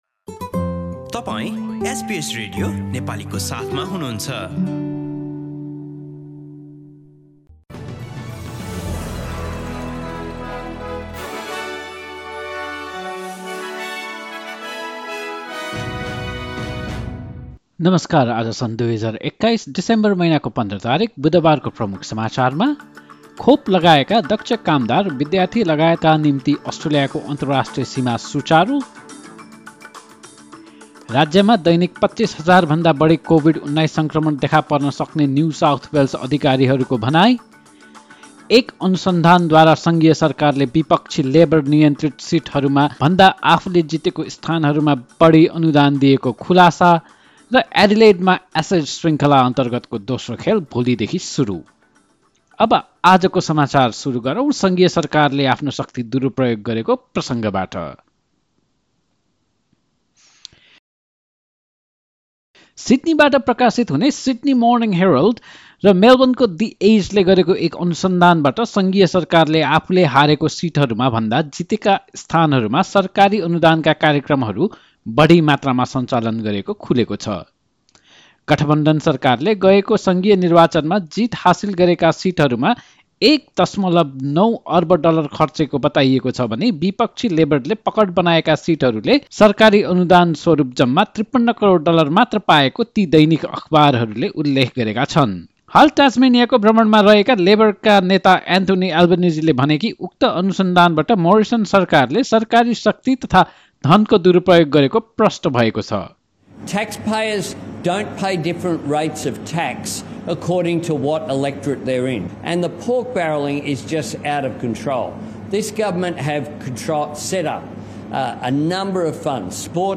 एसबीएस नेपाली अस्ट्रेलिया समाचार: बुधवार १५ डिसेम्बर २०२१